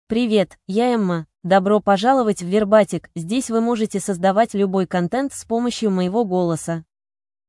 Emma — Female Russian AI voice
Emma is a female AI voice for Russian (Russia).
Voice sample
Female